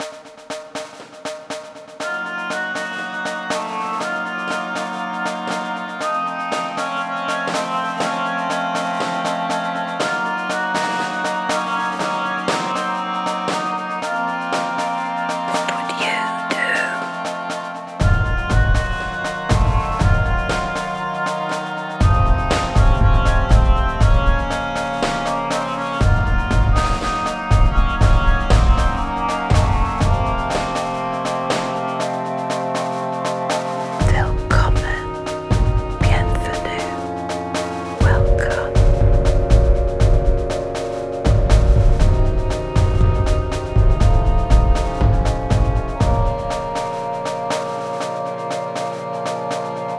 a multi-channel sound installation made up of sculptural forms with different acoustic properties. As audiences move through the space, sound shifts depending on where they stand, how close they are, and how they position their bodies, turning listening into an active, physical experience.